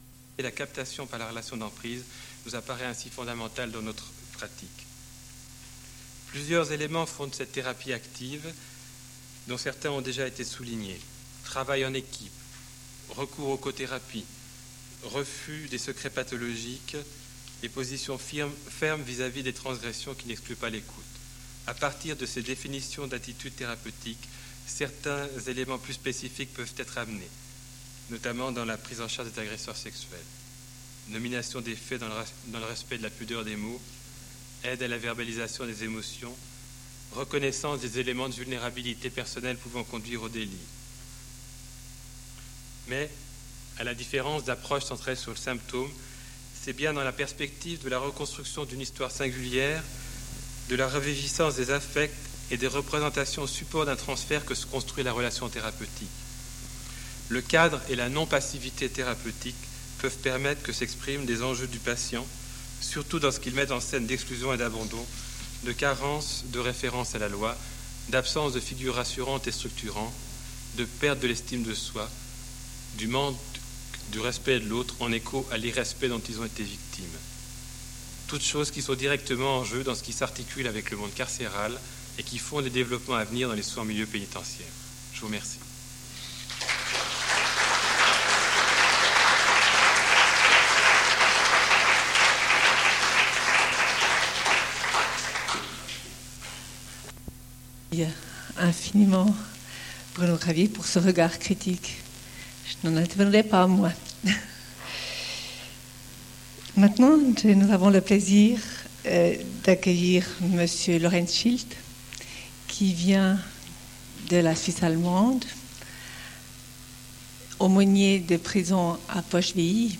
Un fichier MP3 (55.7MB, 58'03'') copie numérique d'une cassette audio (les deux faces ont été jointes en un seul fichier).